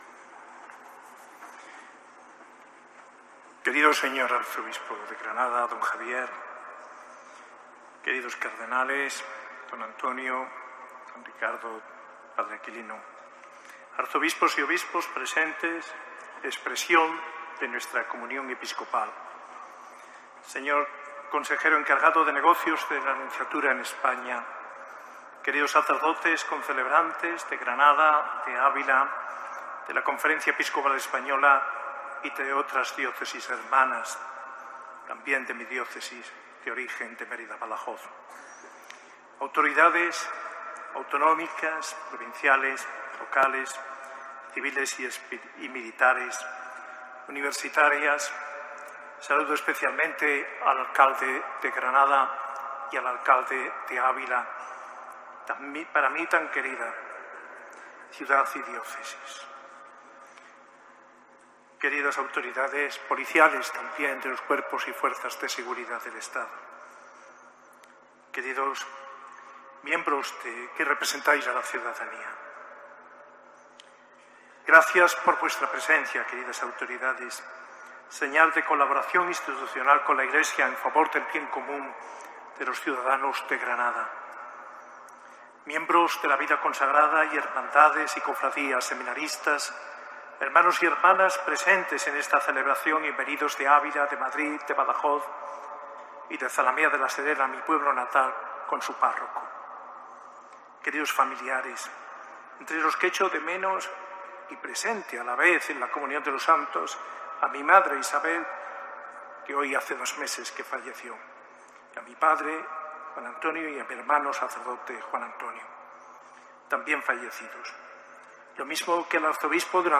Primeras palabras como arzobispo coadjutor de Granada de monseñor José María Gil Tamayo